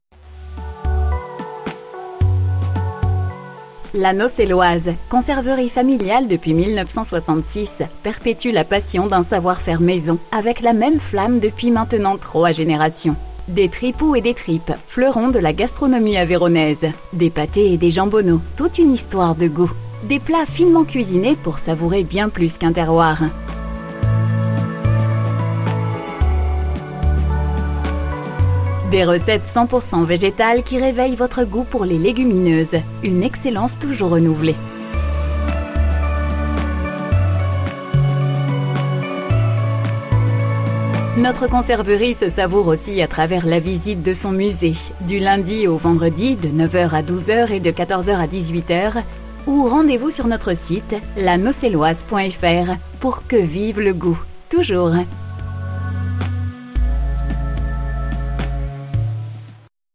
Notre nouveau message vocal
message-vocal-la-naucelloise.mp3